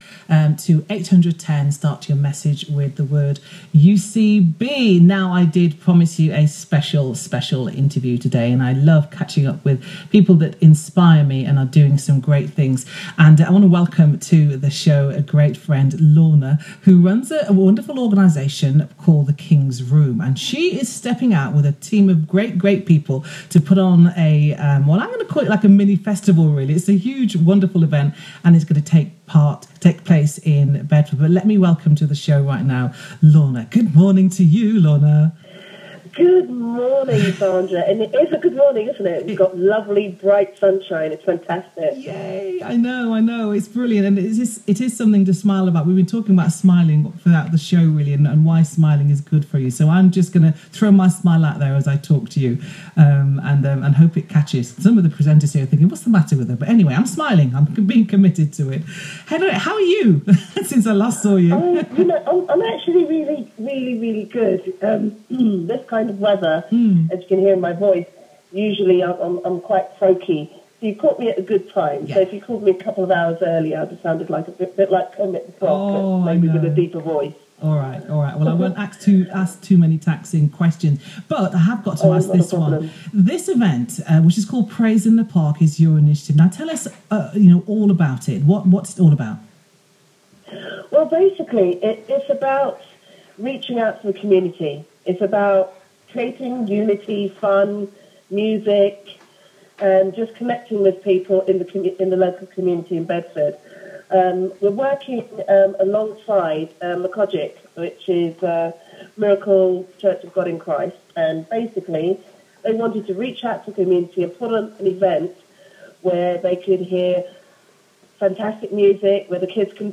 Last month, as part of the promotion for the Praise In The Park event on Saturday 22nd July, I got some air-play on UCB radio. It was so exciting and surreal to hear my song on the radio for the 2nd time.